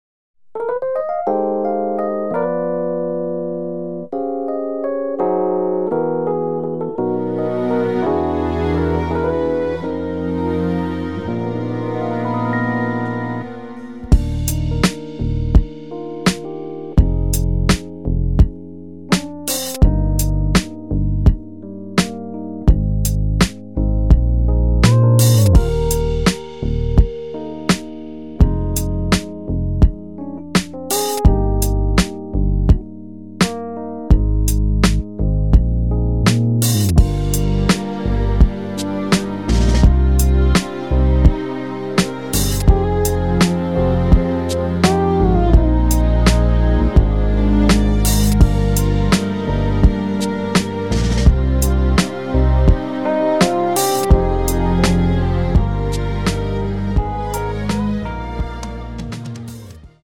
” [공식 음원 MR] 입니다.
Eb
앞부분30초, 뒷부분30초씩 편집해서 올려 드리고 있습니다.